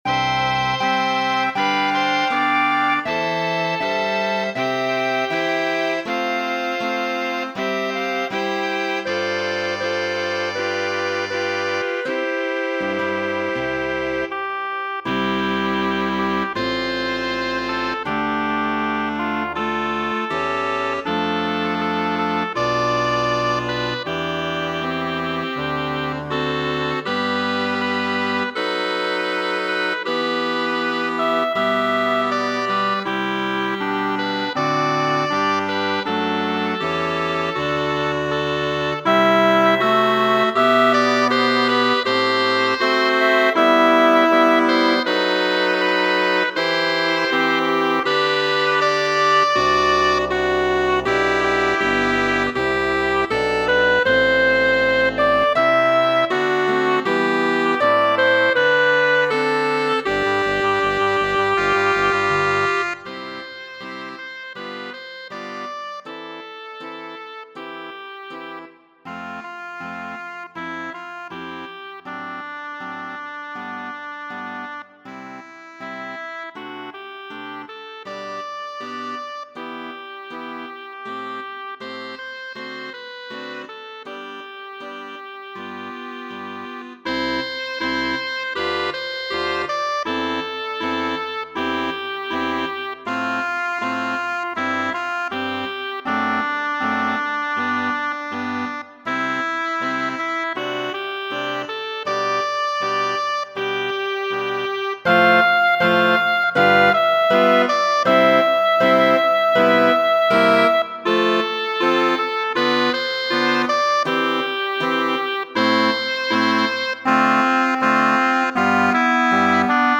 Lando da espero kaj glorioª, marŝo de brita komponisto Elgar.